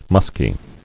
[musk·y]